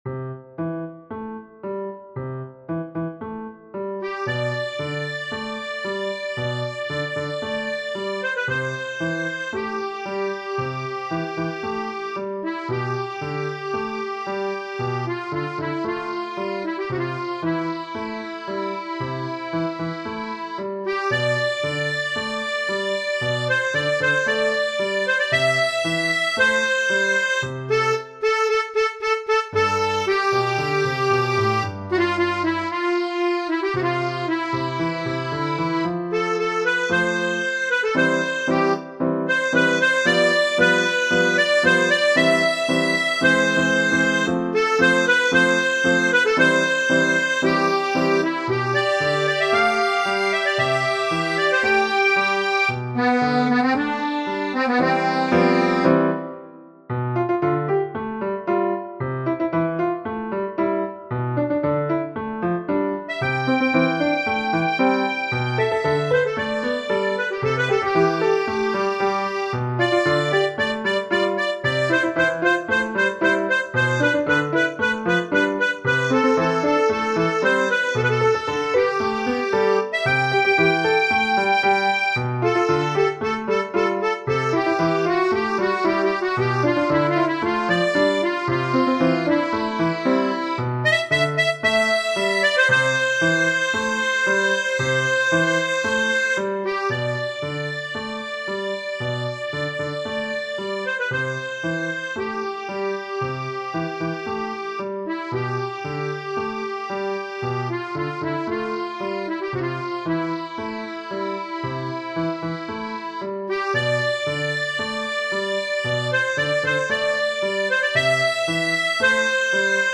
Genere: Ballabili
tango campero